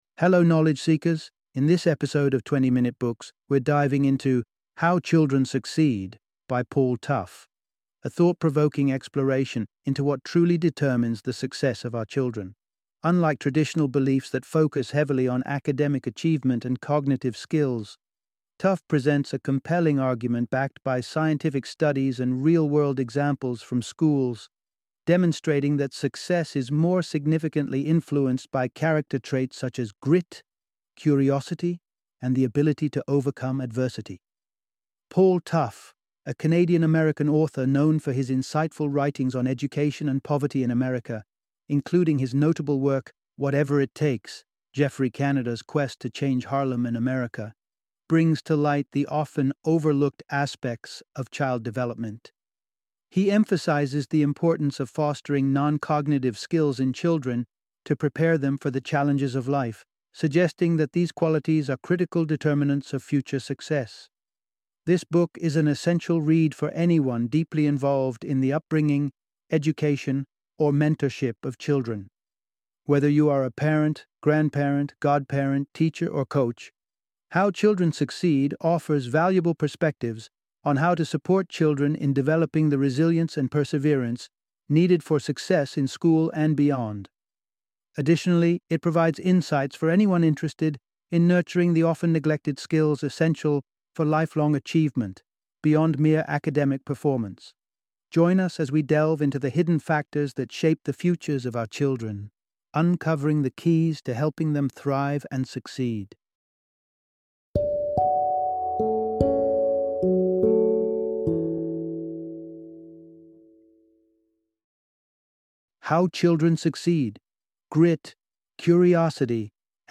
How Children Succeed - Audiobook Summary